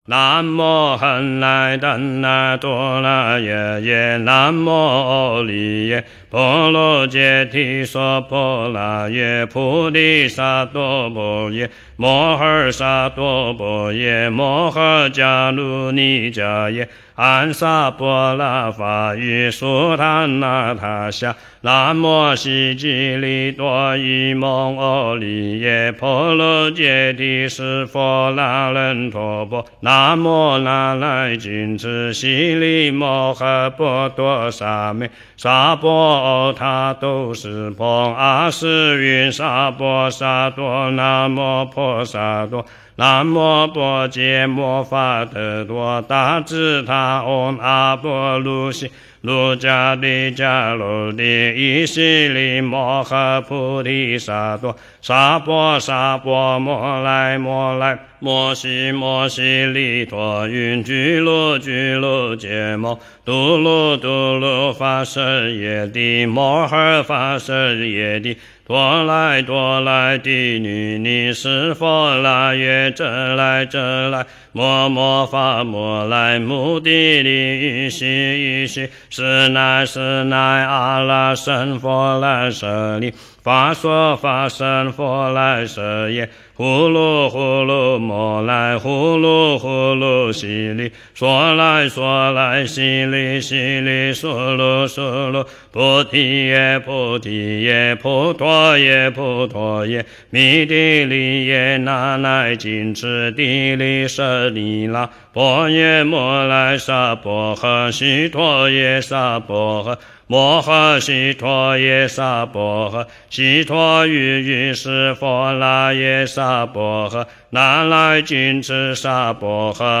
诵经
佛音 诵经 佛教音乐 返回列表 上一篇： 除一切疾病咒 下一篇： 佛说阿弥陀经 相关文章 金刚萨埵心咒--上海梵语合唱团及管弦乐团 金刚萨埵心咒--上海梵语合唱团及管弦乐团...